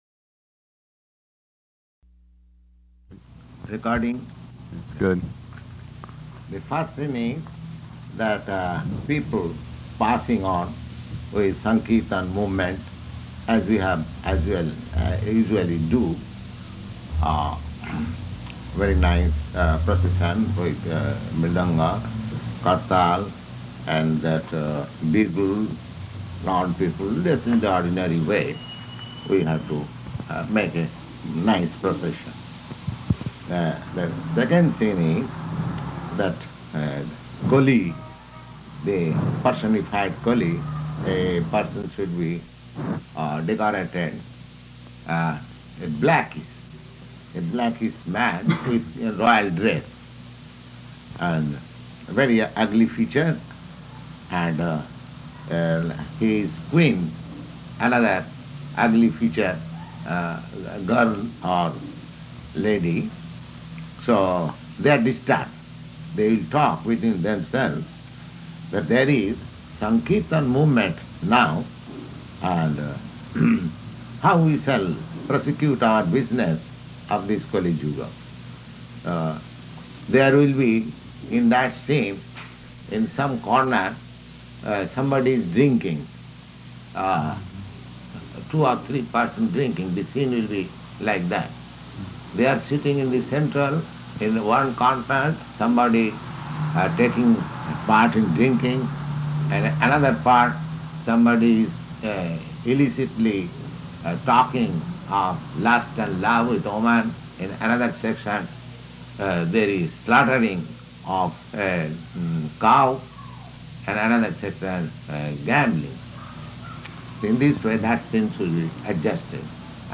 Discourse on Lord Caitanya Play
April 5th 1967 Location: San Francisco Audio file